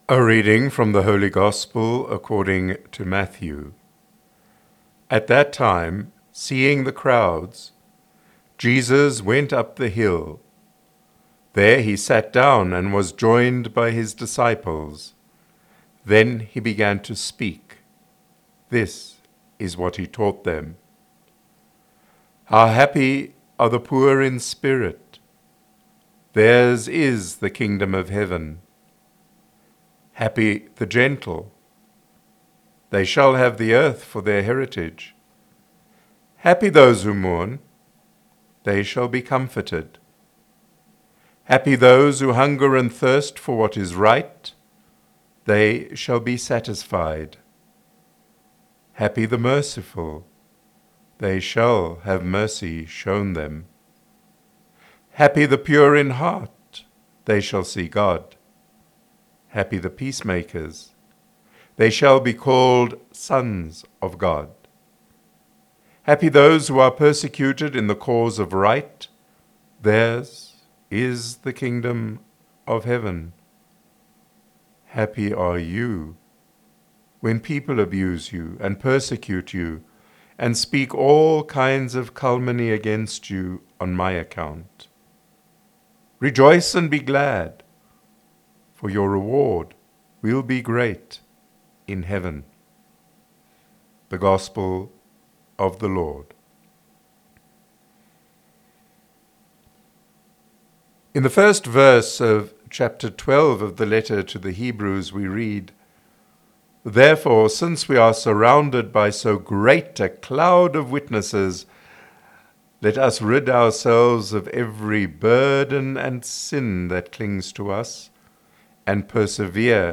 1 Nov 2025 Influencers Podcast: Play in new window | Download For 1 November 2025, All Saints, based on Matthew 5:1-12, sent in from Hyde Park, Johannesburg.
Preaching